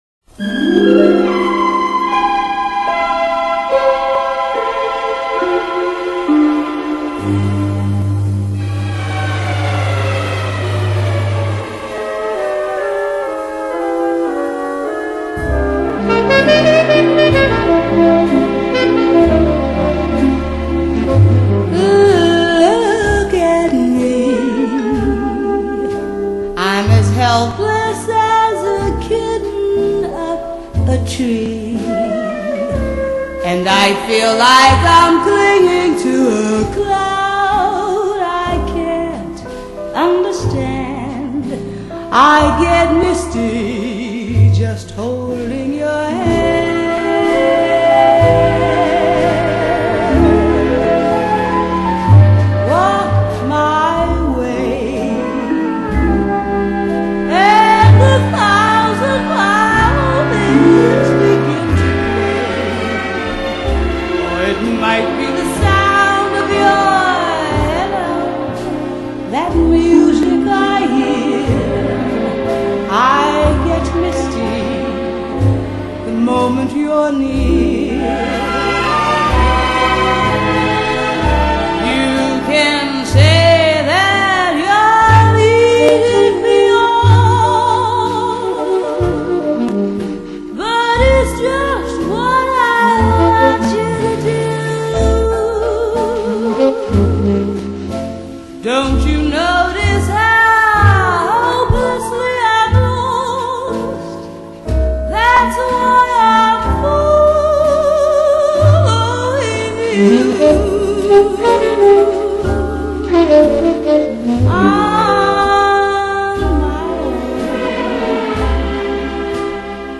Blues And Jazz